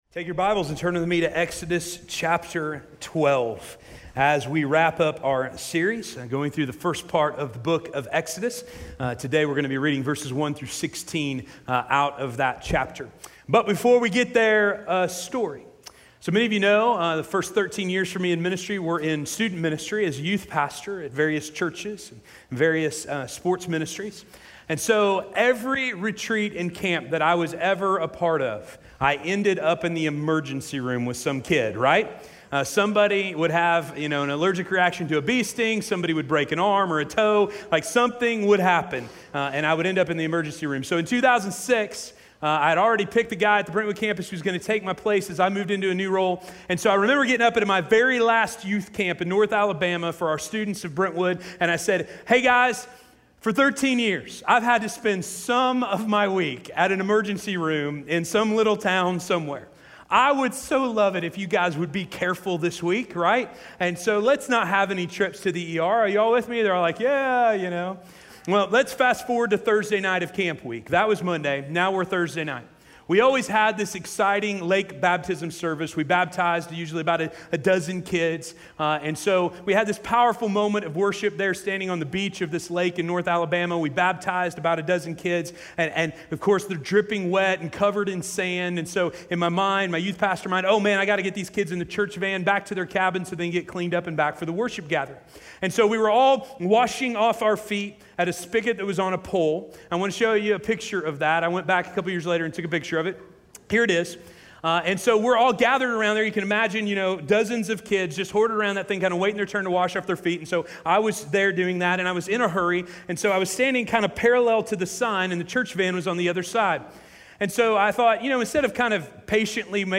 The Means of Evacuation: Passover - Sermon - Station Hill